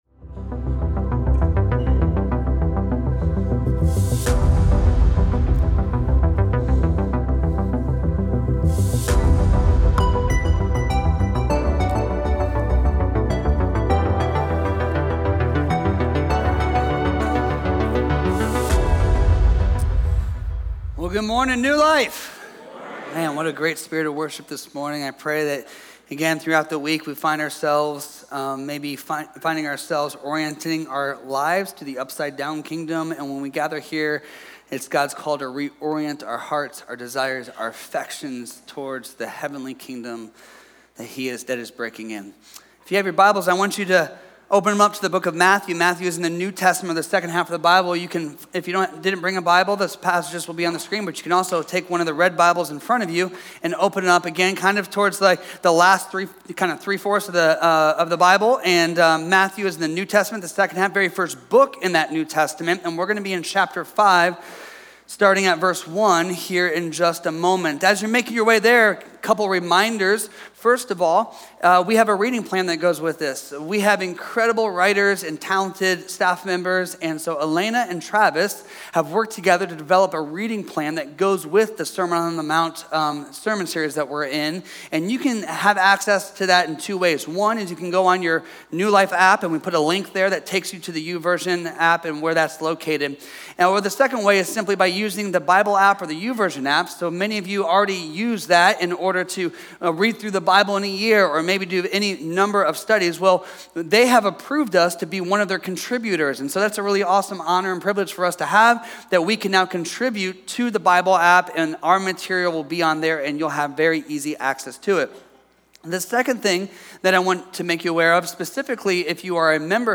A message from the series "Upsidedown Kingdom."